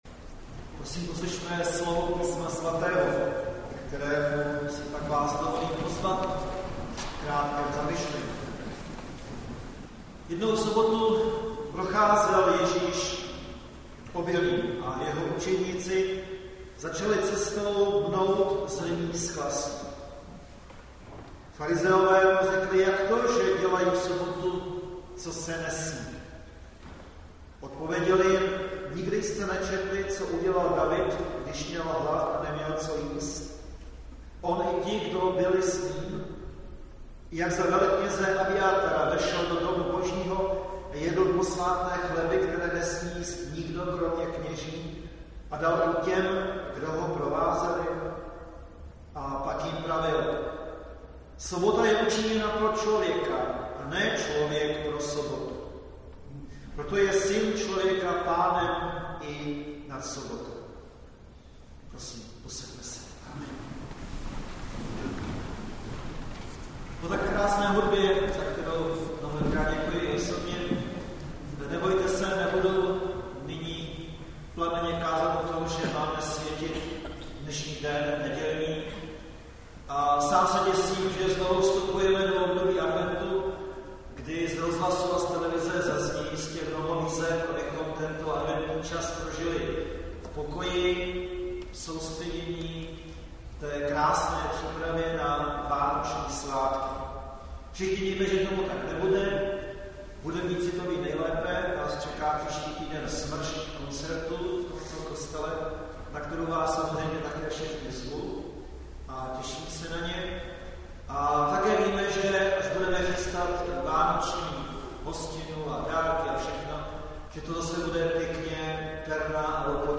záznam kázání